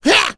Lakrak-Vox_Attack1.wav